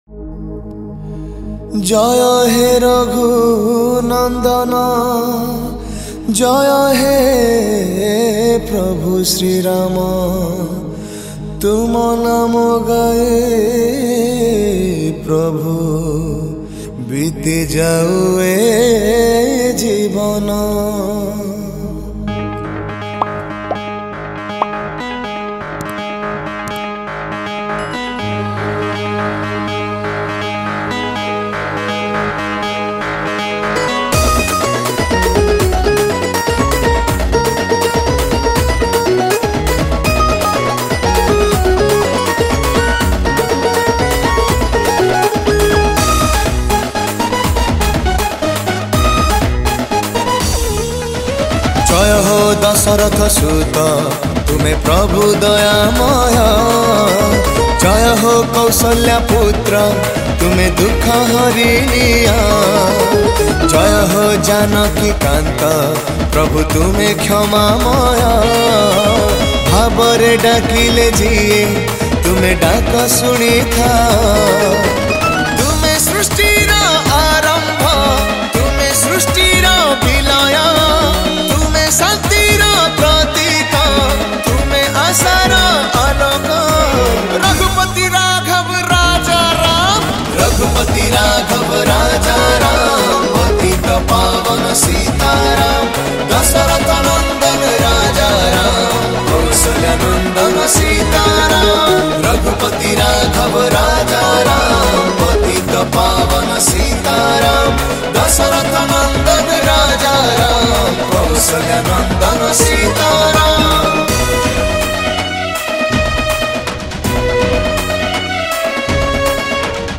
Odia SRIRAM Bhajan
Category : Odia Bhajan Song 2023